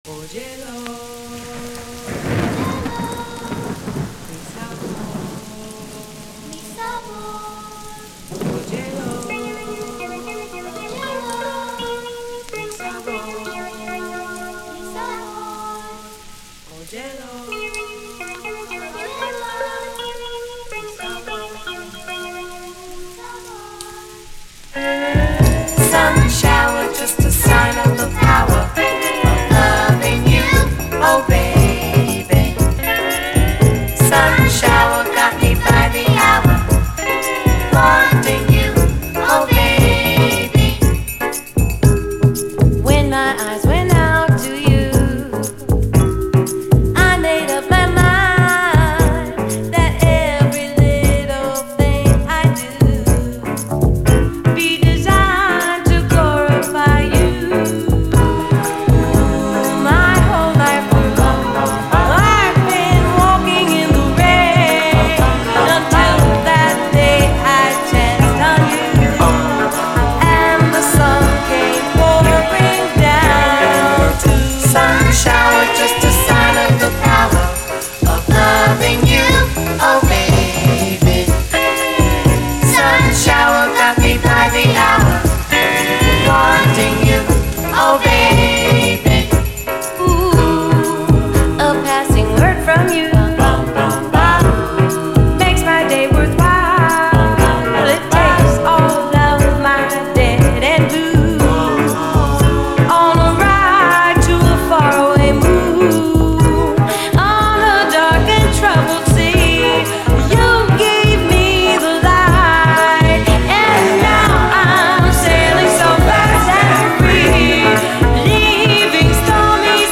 SOUL, 70's～ SOUL, 7INCH
70’Sメロウ・ソウル名曲